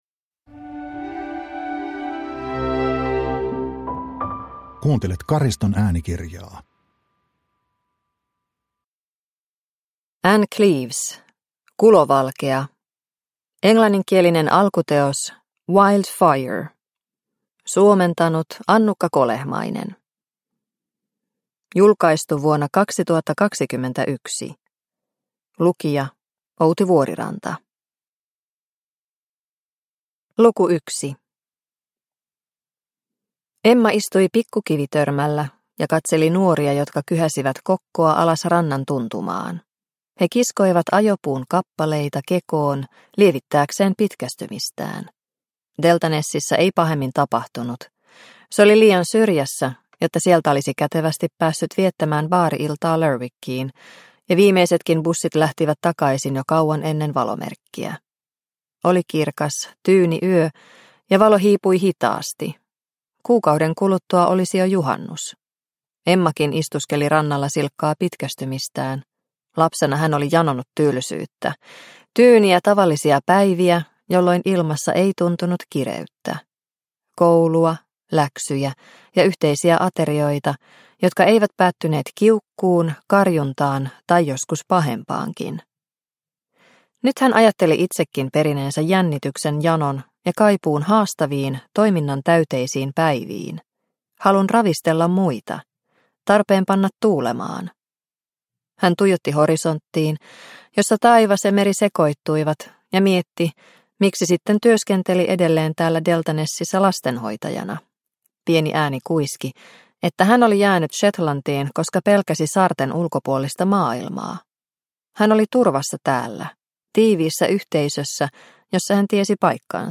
Kulovalkea – Ljudbok – Laddas ner